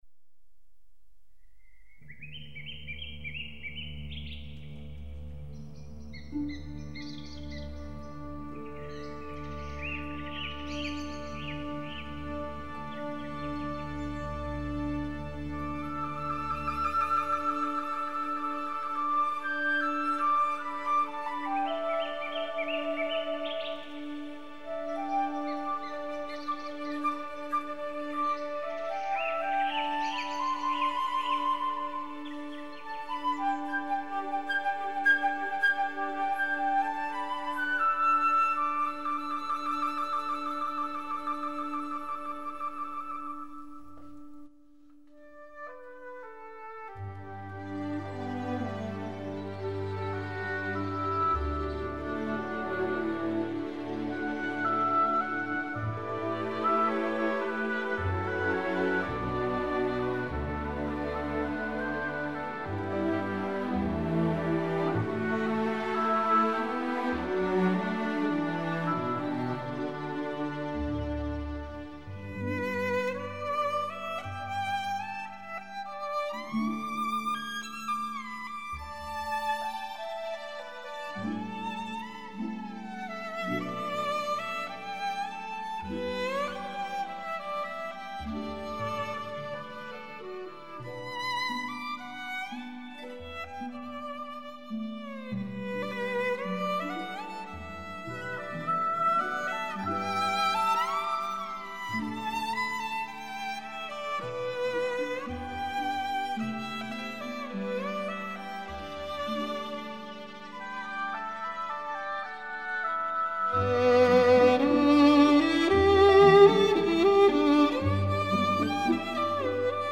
专辑格式：纯音乐
因为它是多才多艺的，既能唱出悠扬如诗般的曲调，又有时让人感到耳花缭乱。